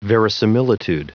Prononciation du mot verisimilitude en anglais (fichier audio)
verisimilitude.wav